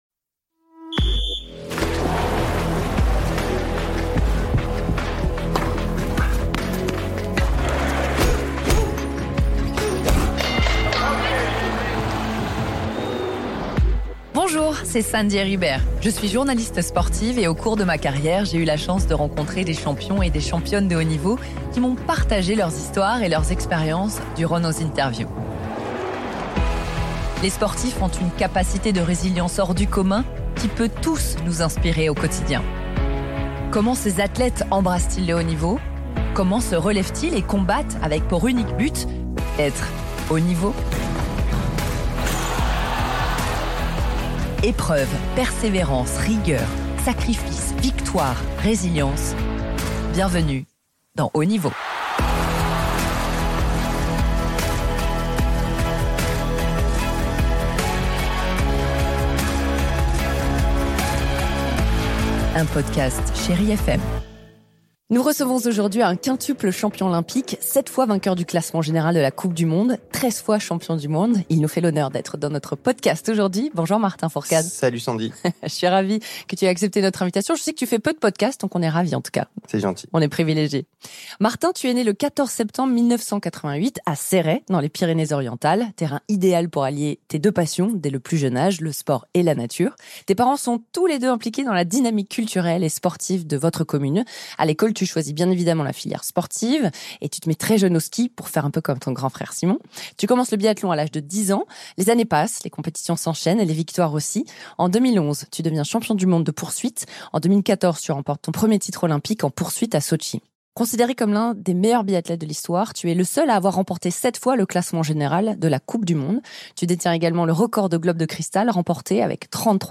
Dans ce premier épisode, Sandy Heribert reçoit Martin Fourcade.